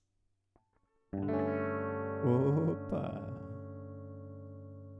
Eu quero gravar um áudio de 5 segundos, 16bits, 44100Hz e usando todos os 2 canais da interface. Vou plugar minha guitarra no segundo canal e bora ver se vai sair fumaça.
Recording WAVE 'opa.wav' : Signed 16 bit Little Endian, Rate 44100 Hz, Stereo